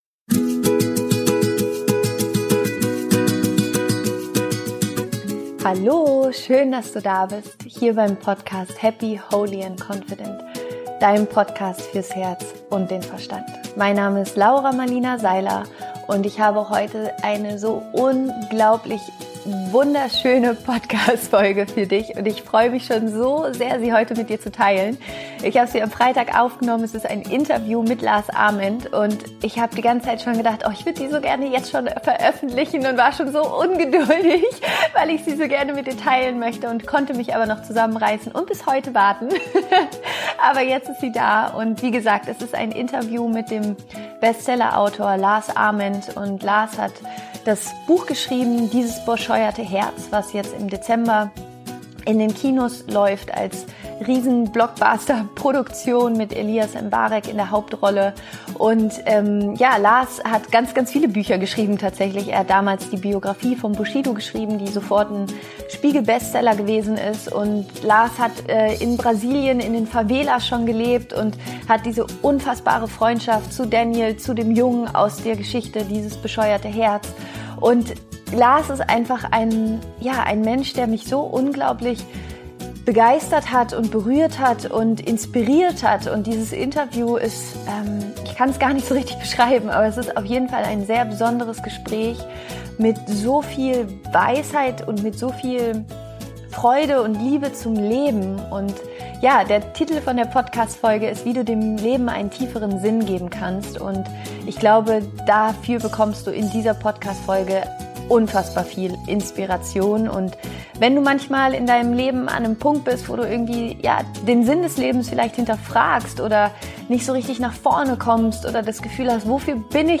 Wie du deinem Leben mehr Tiefe gibst - Interview Special